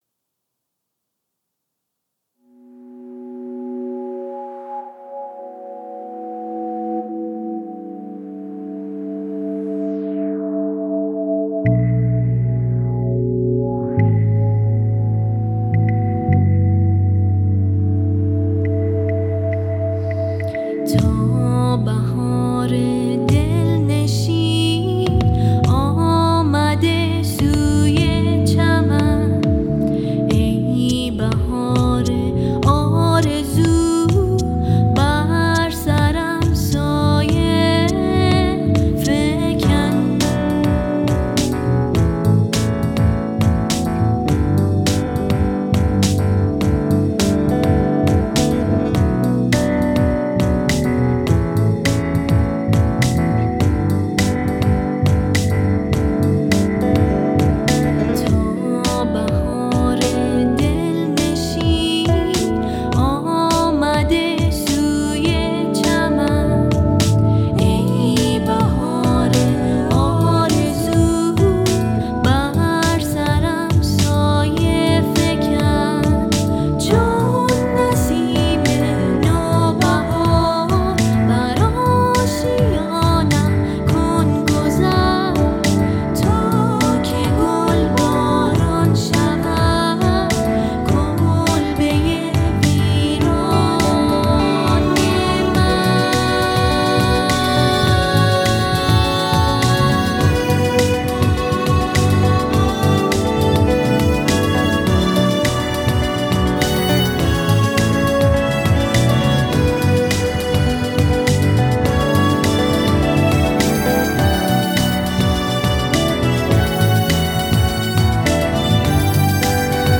نسخه‌ی پست راک